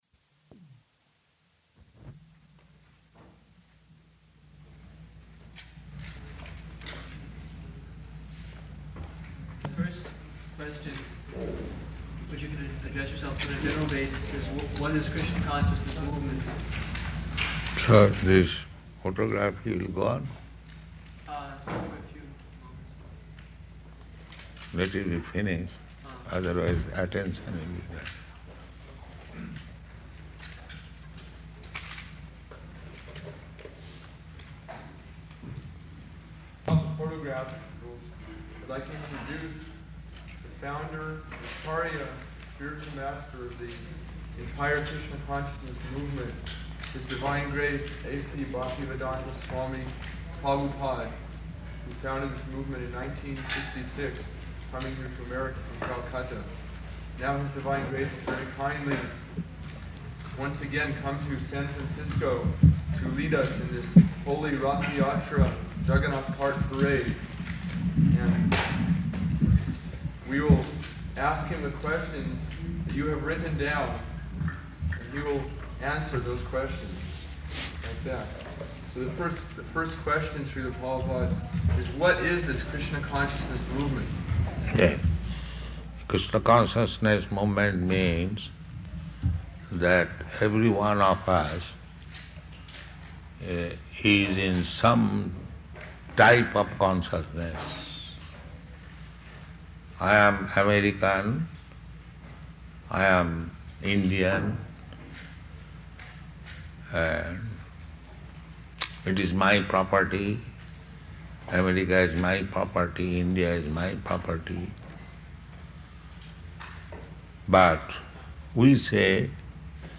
Press Conference
Type: Conversation
Location: San Francisco
[cameras clicking] Prabhupāda: So this photograph will go on?